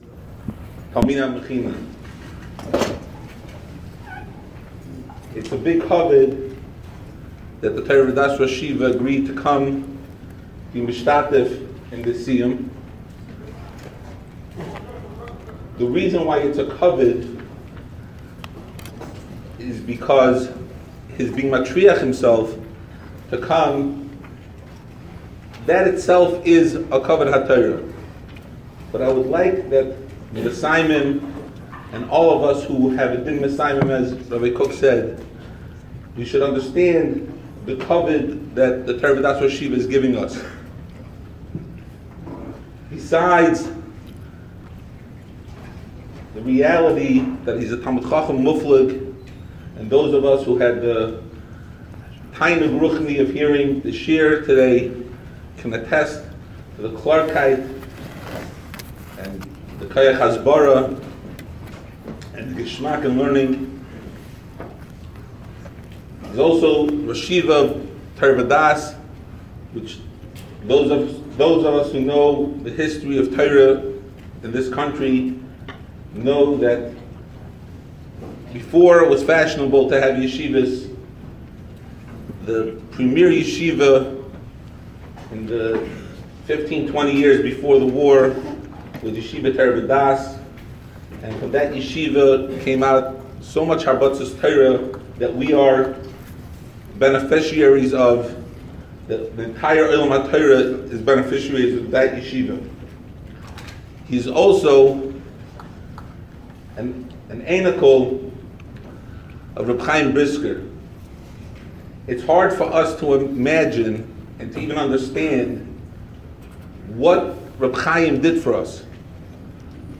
Mechina, Special Lecture - Ner Israel Rabbinical College
On Tuesday June 25th the Mechina celebrated a Siyum on Maseches Babba Kamma.
Many parents and grandparents of the Mesaymim were in attendance Shepping Nachas from their children’s accomplishments.